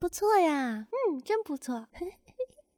鼓掌2.wav
鼓掌2.wav 0:00.00 0:02.78 鼓掌2.wav WAV · 240 KB · 單聲道 (1ch) 下载文件 本站所有音效均采用 CC0 授权 ，可免费用于商业与个人项目，无需署名。
人声采集素材/人物休闲/鼓掌2.wav